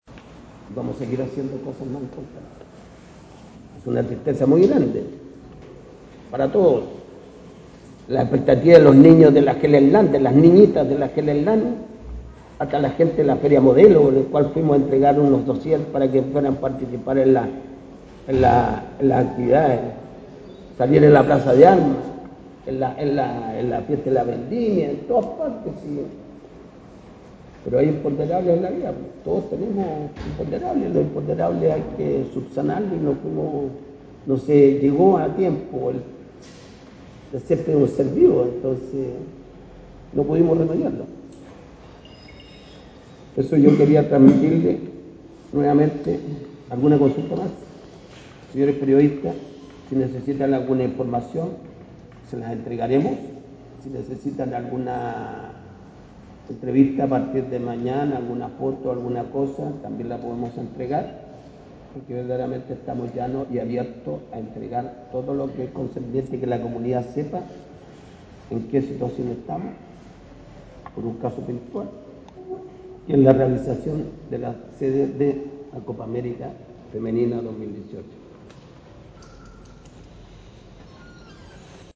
En una conferencia de prensa a las 13:30 horas la municipalidad de Ovalle dio a conocer su postura frente a los hechos ocurridos que dejan a Ovalle perdiendo la anhelada sede de Copa América Femenina. Escucha aquí parte de las palabras de Claudio Rentería, Alcalde de Ovalle donde se comprometió a transparentar el proceso a través de los medios de comunicación.